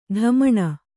♪ ḍhamaṇa